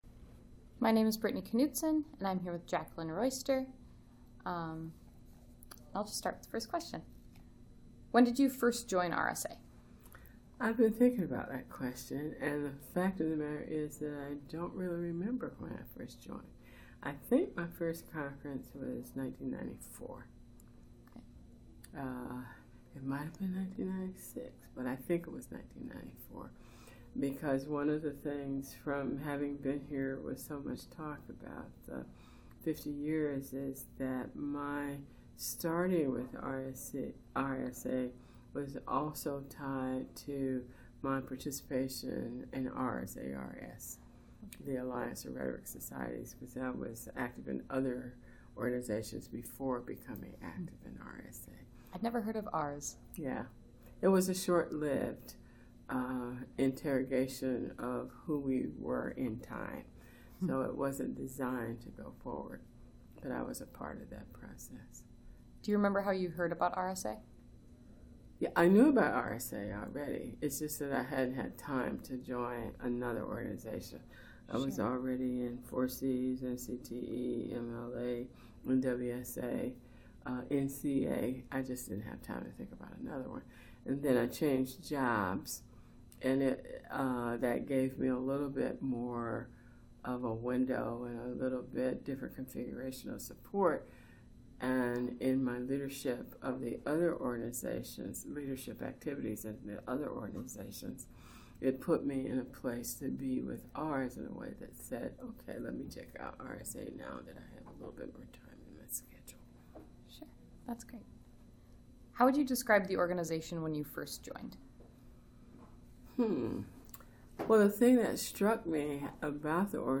Oral History Item Type Metadata
2018 RSA Conference in Minneapolis, Minnesota